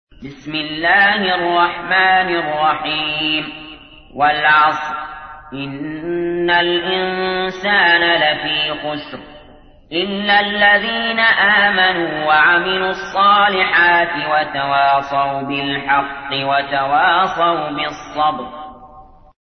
تحميل : 103. سورة العصر / القارئ علي جابر / القرآن الكريم / موقع يا حسين